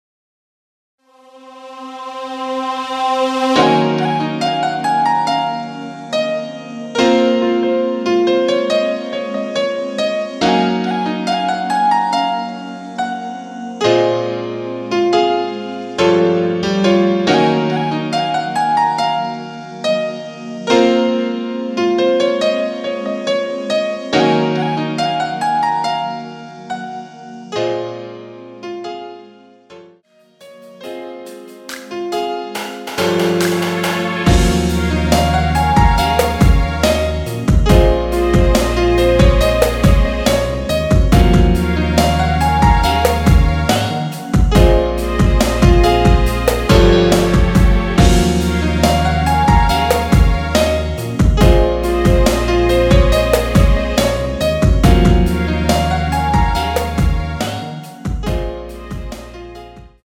Bbm
앞부분30초, 뒷부분30초씩 편집해서 올려 드리고 있습니다.
중간에 음이 끈어지고 다시 나오는 이유는